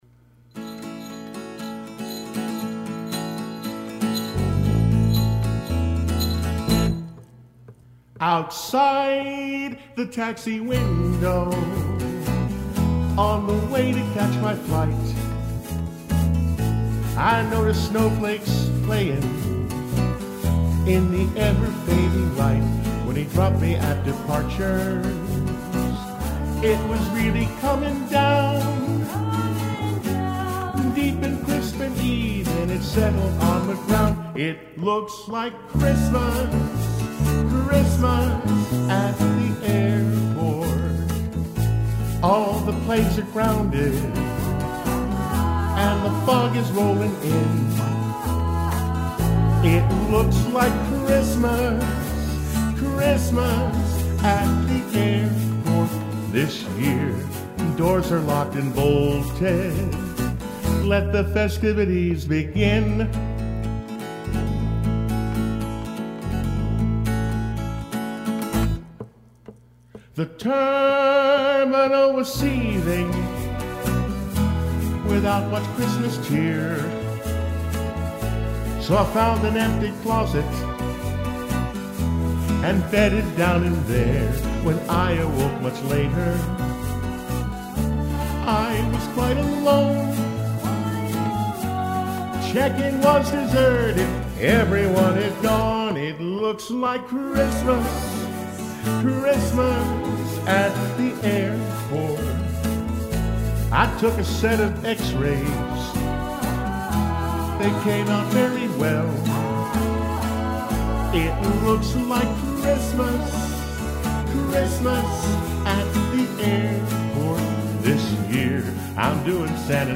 Lead Vocal
Rhythm guitar, percussion, keys, backup vocal
Bass guitar